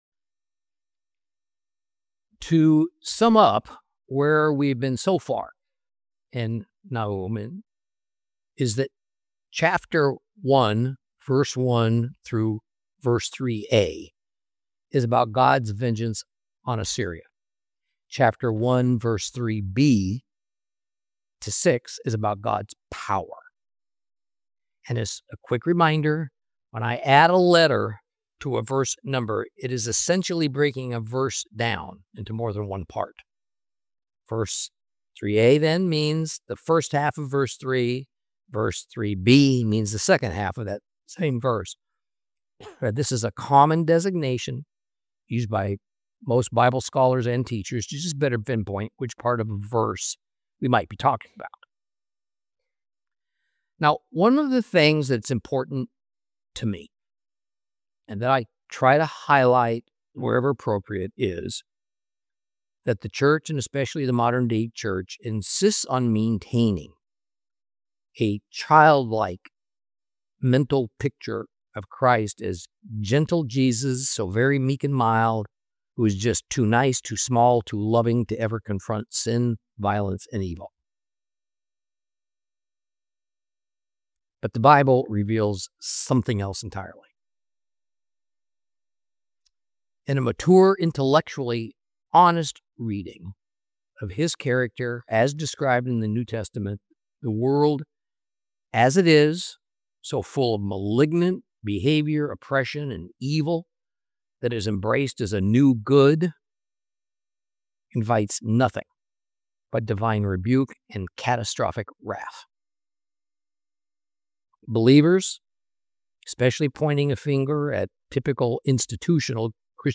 Teaching from the book of Nahum, Lesson 3 Chapter 1 continued.